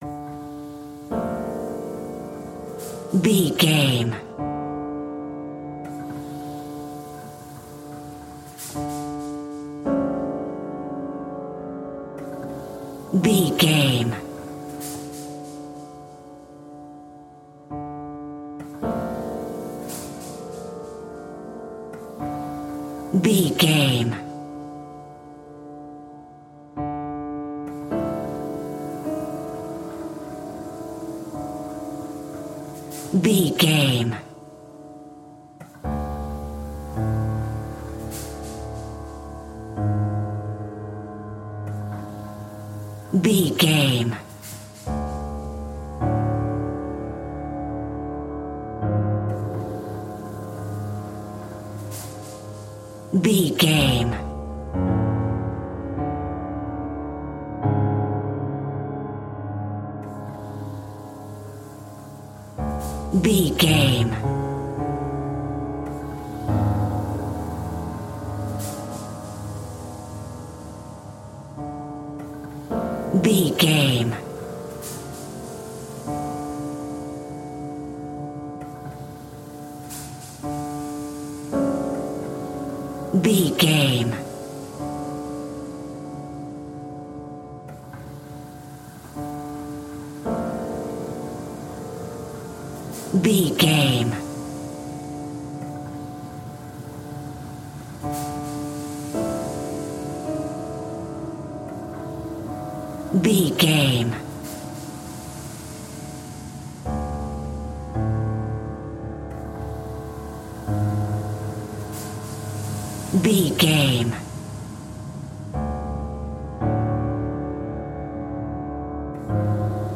royalty free music
In-crescendo
Thriller
Aeolian/Minor
ominous
suspense
eerie
horror music
Horror Pads
horror piano
Horror Synths